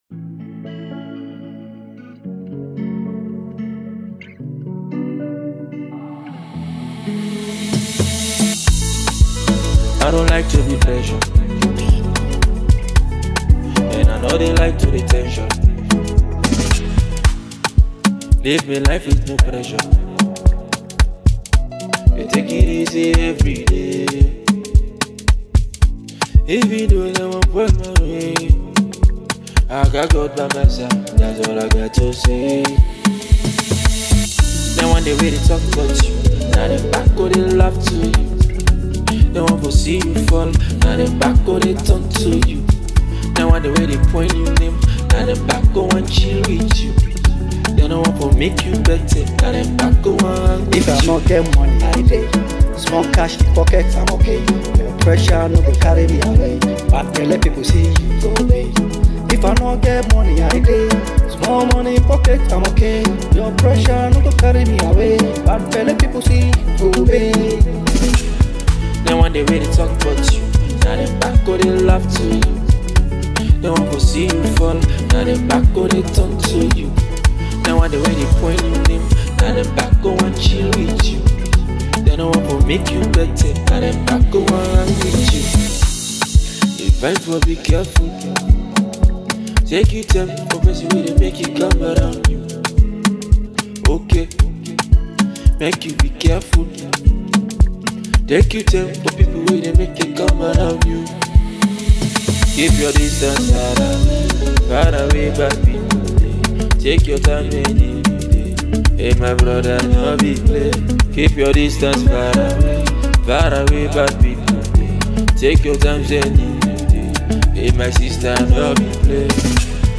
is also a cool song with a steady flow